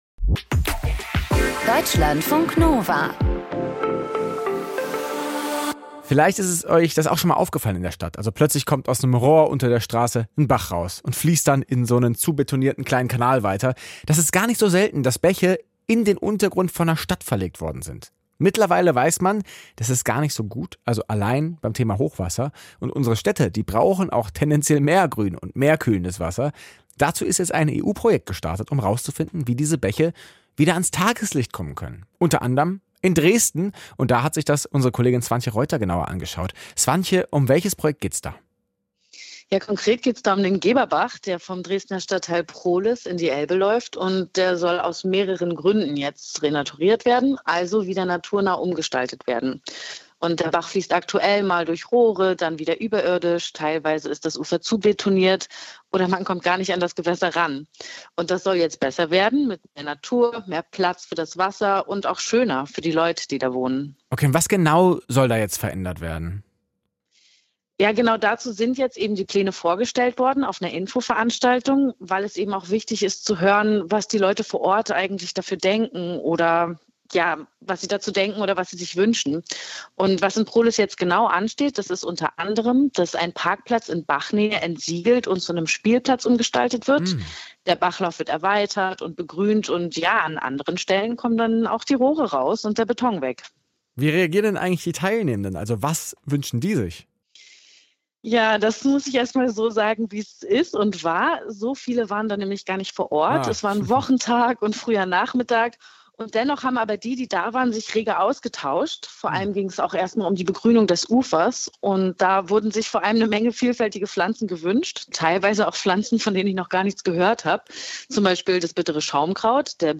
Gut recherchiert, persönlich erzählt – das ist die Reportage von Deutschlandfunk Kultur.